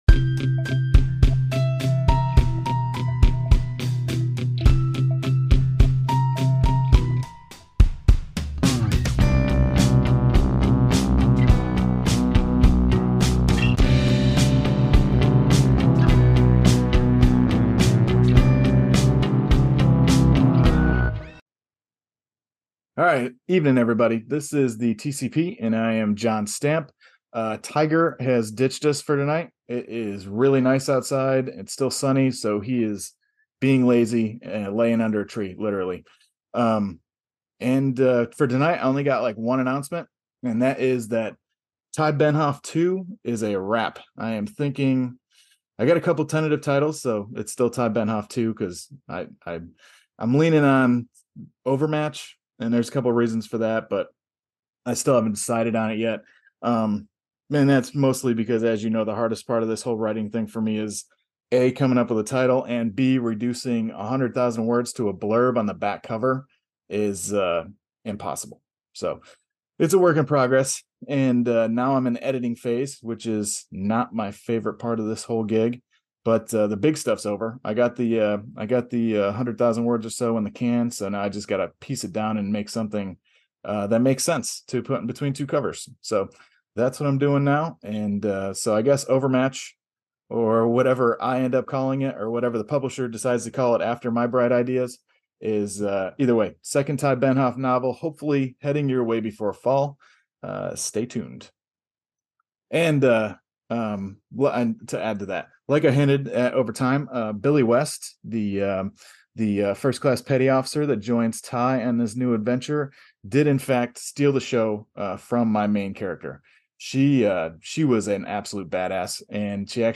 TCP Ep 40: Interview